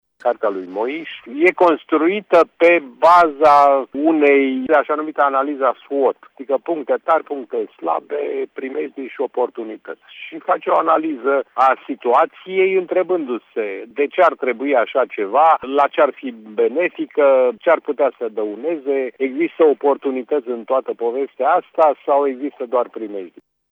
Jurnalistul